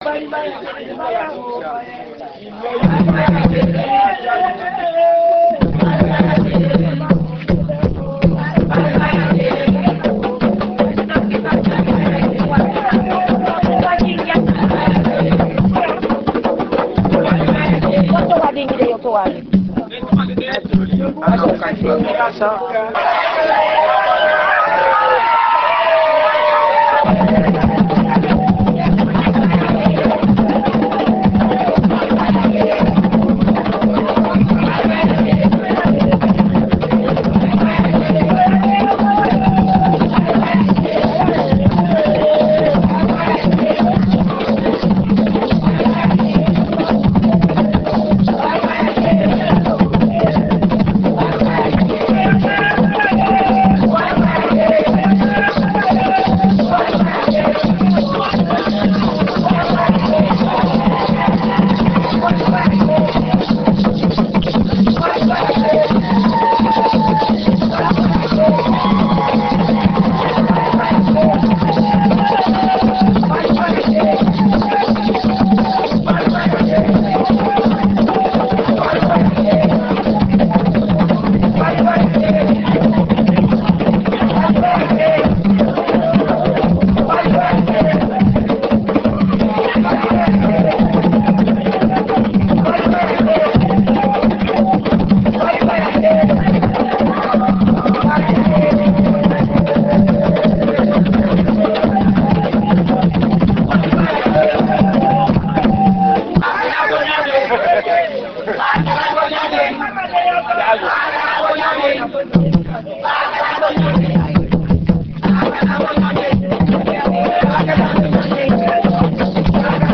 enregistrement durant une levée de deuil (Puubaaka)
danse : awassa (aluku) ; levée de deuil ;
Genre songe
Pièce musicale inédite